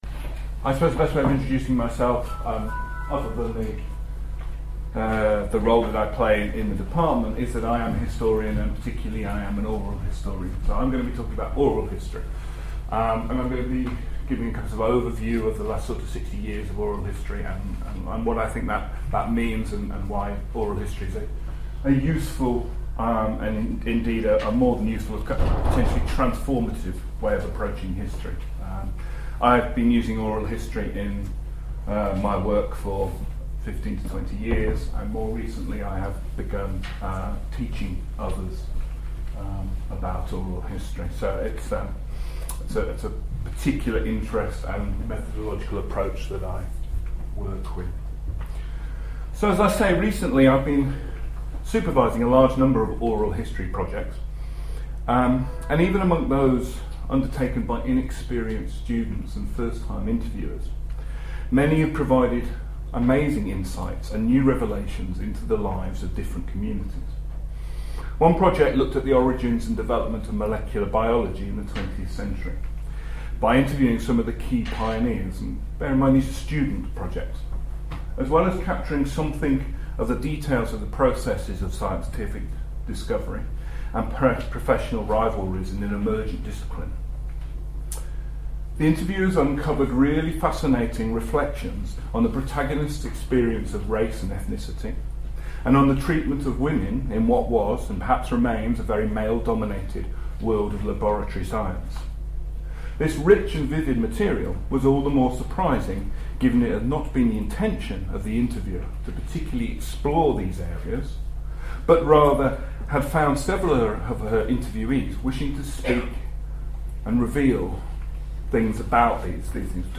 Presentation
at the Hidden Histories Symposium, September 2011, UCL.